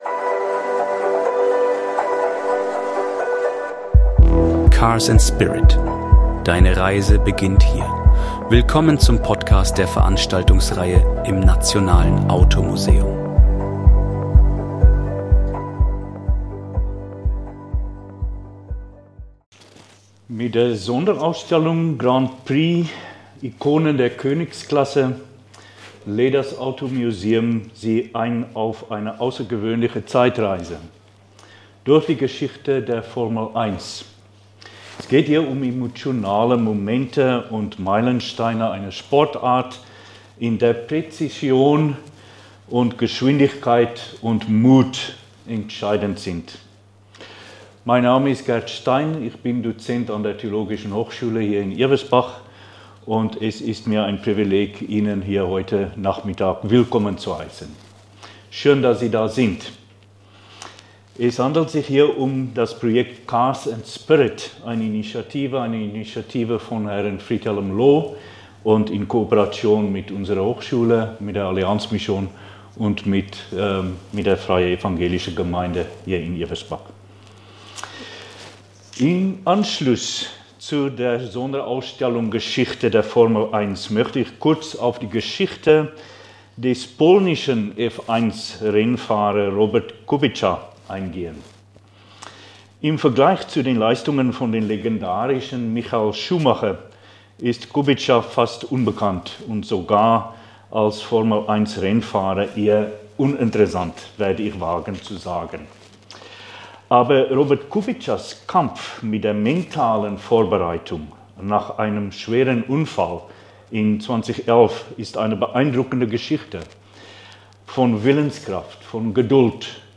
Beschreibung vor 11 Monaten Erleben Sie im Nationalen Automuseum die bewegende Geschichte von Robert Kubica – einem Formel-1-Fahrer, der nach einem schweren Unfall beinahe alles verlor.